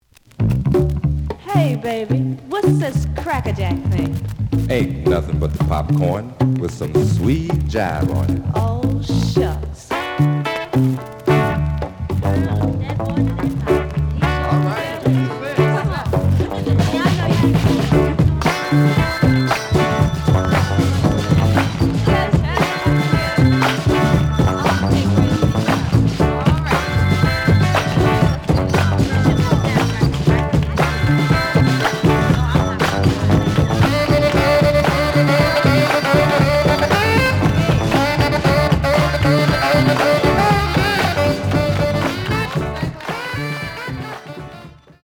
The audio sample is recorded from the actual item.
●Genre: Funk, 70's Funk
Some noise on A side.